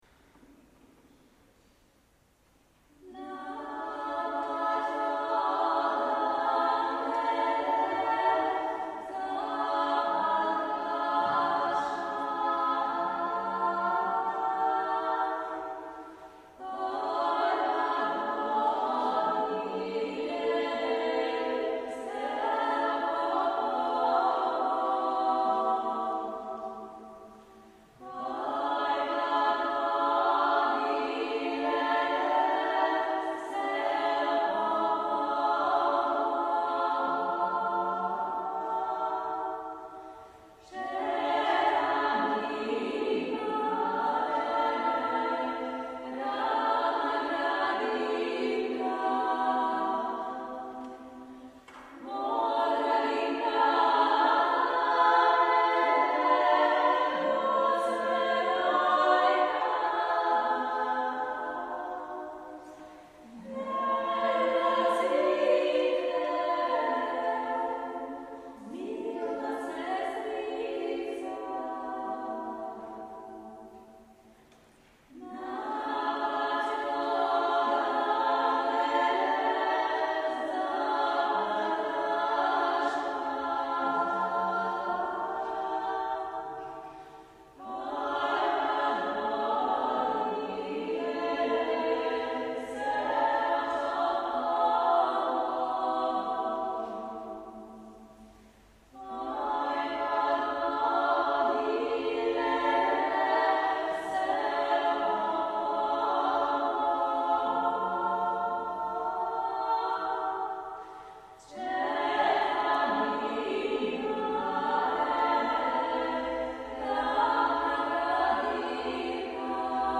concerts de polyphonies du monde
4 à 10 chanteurs
extraits de concerts en mp3 de la Compagnie Loucine
enregistrés à Chaudes Aigues, août 06 & à Trets, novembre 06